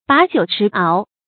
把酒持螯 bǎ jiǔ chí áo
把酒持螯发音